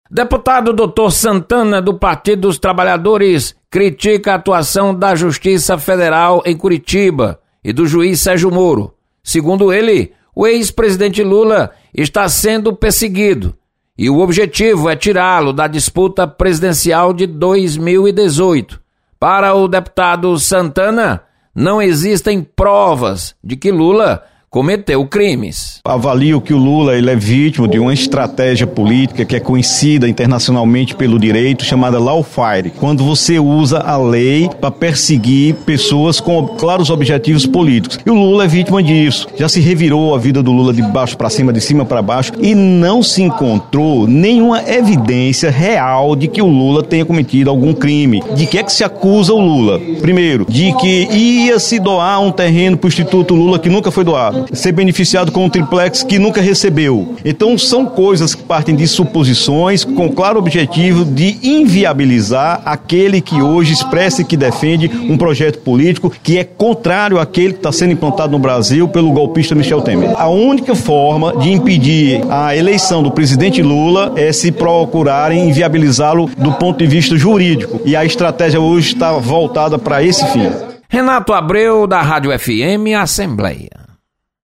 Deputado Dr. Santana critica atuação da justiça. Repórter